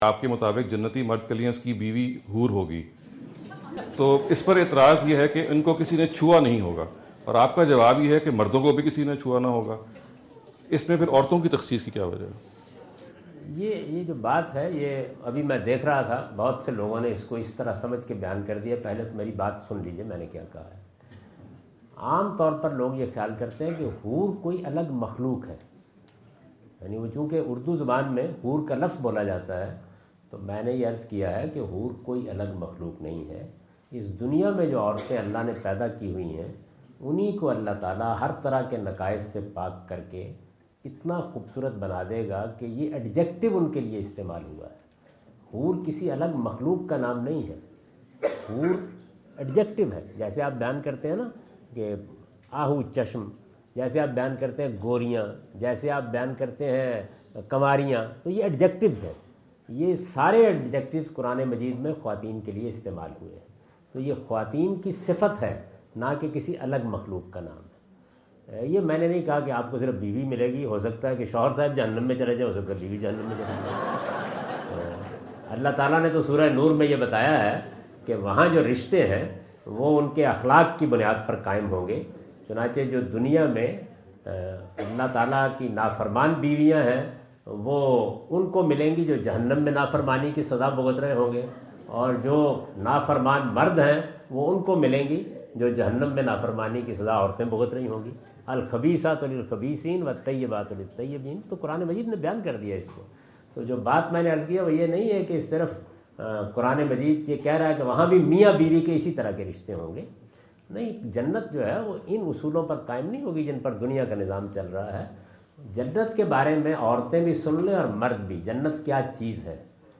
Javed Ahmad Ghamidi answer the question about "Marriage Partners in Heaven" asked at Corona (Los Angeles) on October 22,2017.
جاوید احمد غامدی اپنے دورہ امریکہ 2017 کے دوران کورونا (لاس اینجلس) میں "جنت کے ازدواجی جوڑے" سے متعلق ایک سوال کا جواب دے رہے ہیں۔